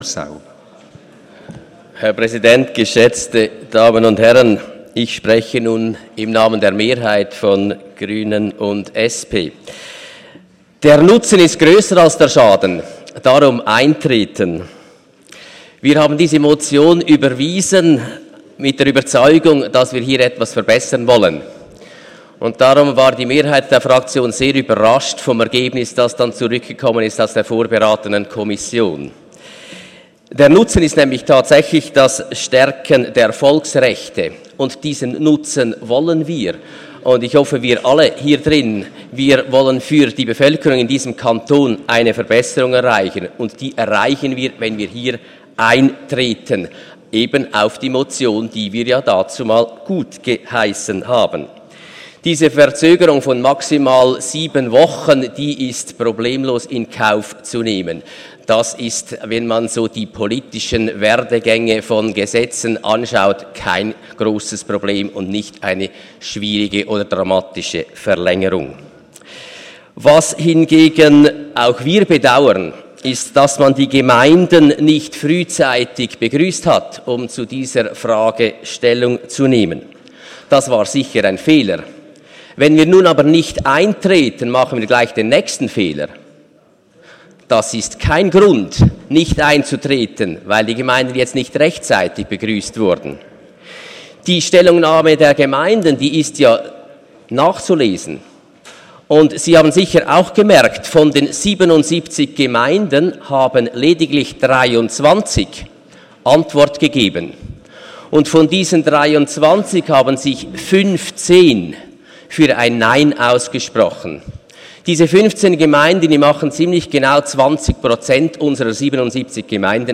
Session des Kantonsrates vom 20. und 21. Februar 2017